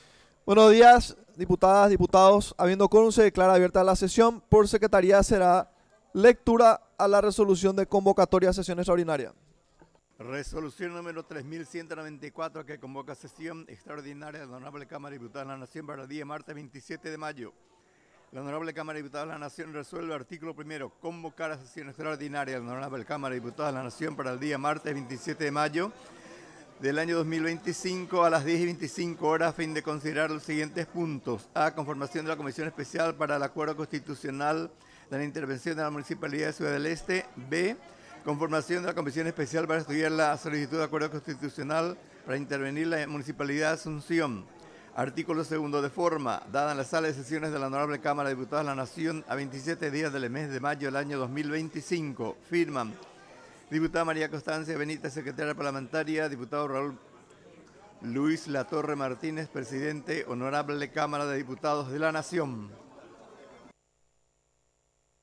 Inicio de la sesión y consideración del acta de la sesión anterior